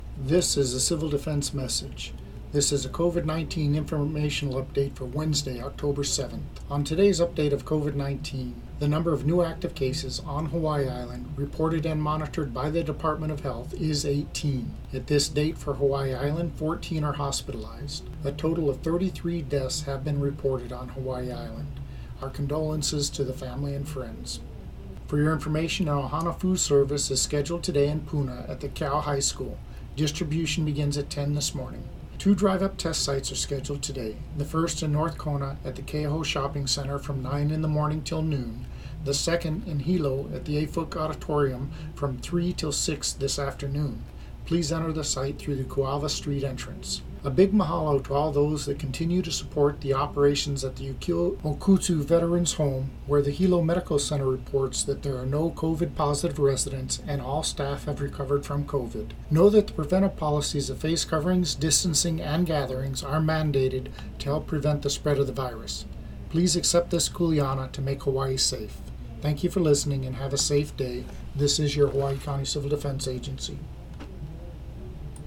UPDATE(10:32 a.m.) – The Hawaiʻi County Civil Defense issued the following radio message on Wednesday morning: